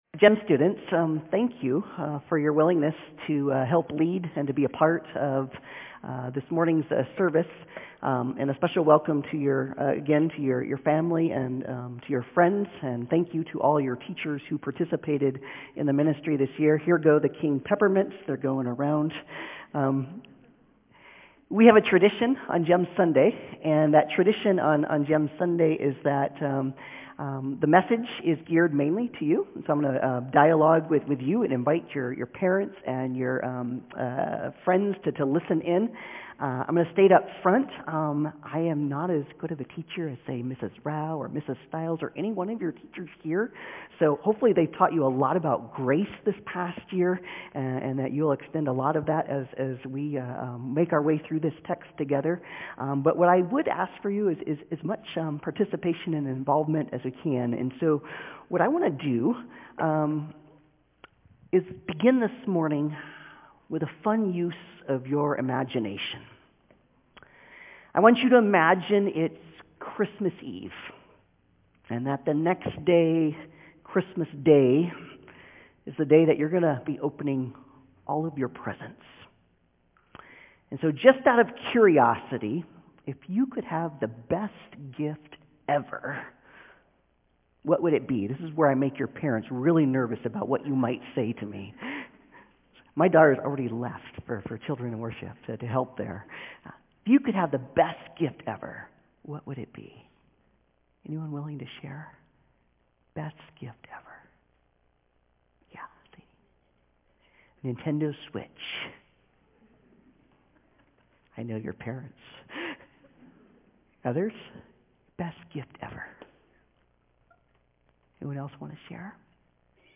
Romans 12:1-5 Service Type: Sunday Service « Remember How He Told You…